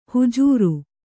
♪ hujūru